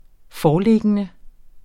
Udtale [ ˈfɒː- ]